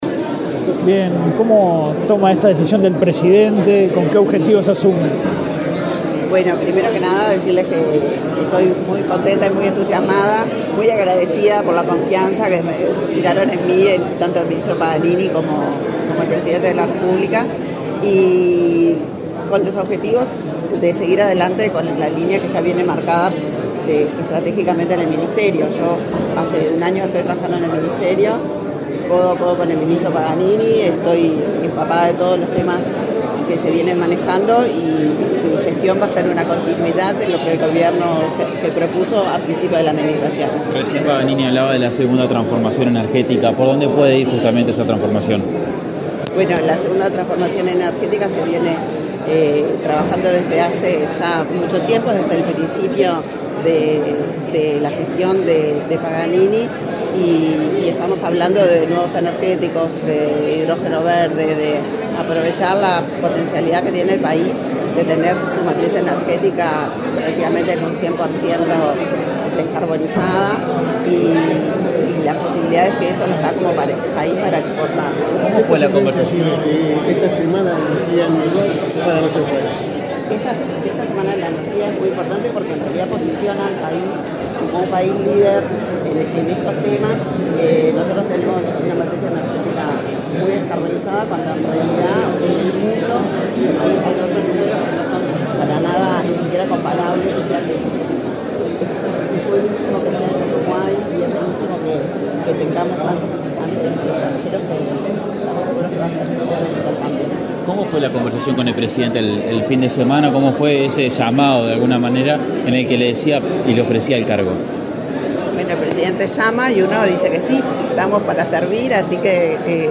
Declaraciones de la directora general del MIEM, Elisa Facio
El ministro de Industria, Omar Paganini, y la directora general de esa cartera, Elisa Facio, participaron, este lunes 6 en Montevideo, de la apertura de la VIII Semana de la Energía y Primer Foro de Inversión en Transición Energética para América Latina, organizado por la Agencia Internacional de Energías Renovables (IRENA). Luego, Facio dialogó con la prensa.